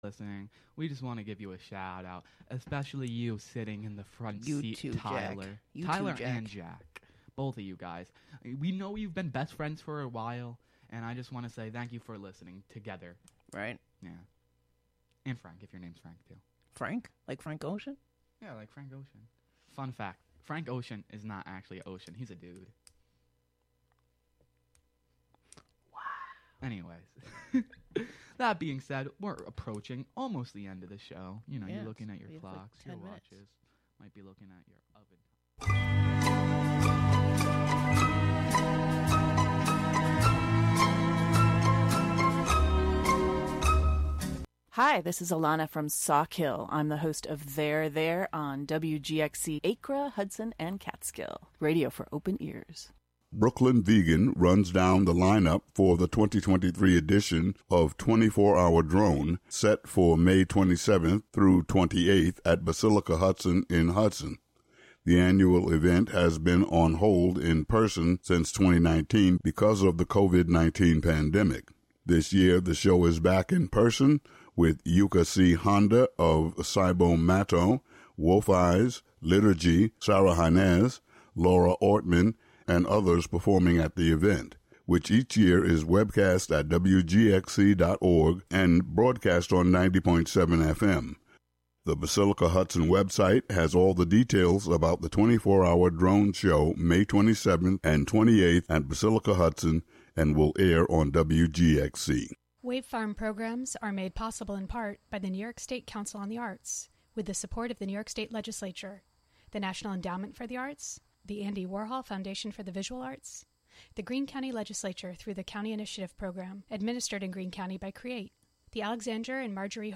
This music mix show